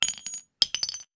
weapon_ammo_drop_19.wav